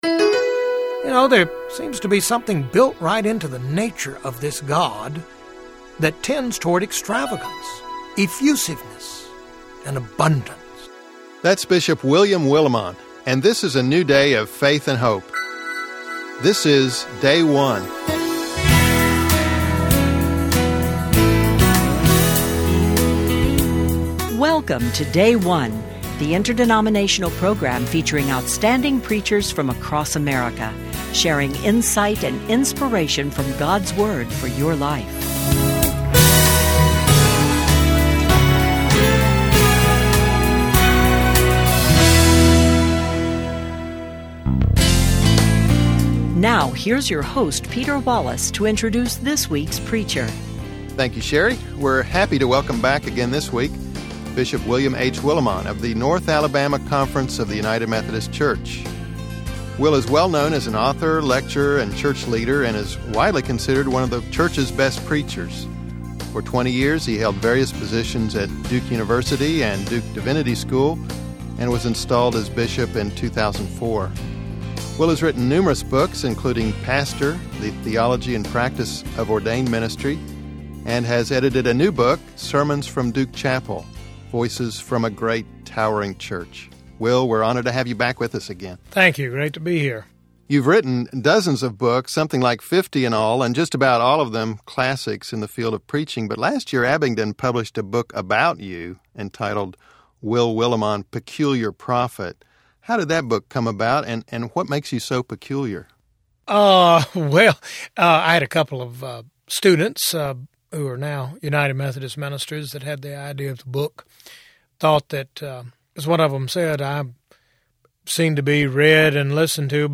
The Rev. Dr. William H. Willimon Organization: Duke Divinity School, Durham, NC Denomination: United Methodist Church 11th Sunday After Pentecost Matthew 14:13-21